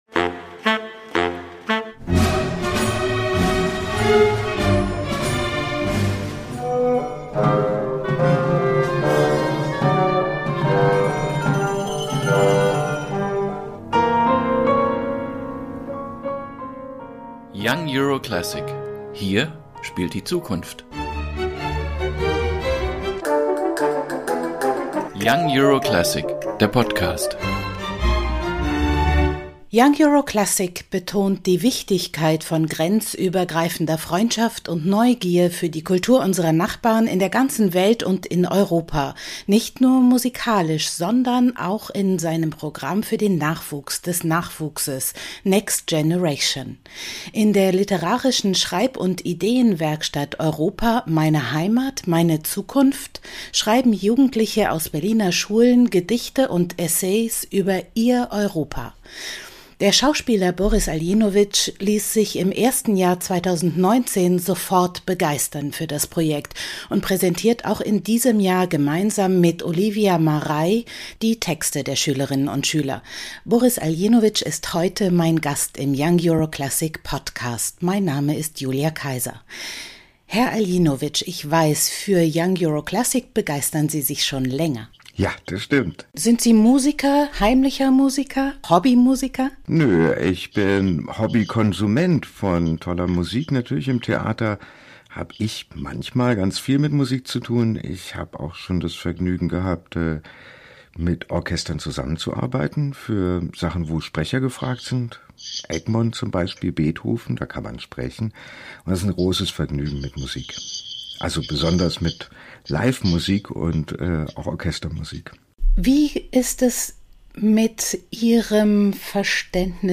Welches Verständnis von Europa- Boris Aljinovic selbst hat, inwiefern ihn die Essays und Gedichte der Berliner Jugendlichen inspirieren und wo in seinem eigenen Leben Europa und ihre Ideale aufblitzen, erzählt er in dieser Folge des Young Euro Classic Podcasts. Das Gespräch